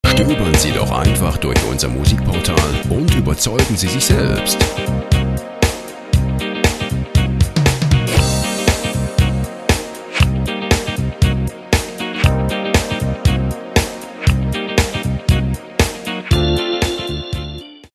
Jazz Pop
typische 70er Jahre Disco zu der Zeit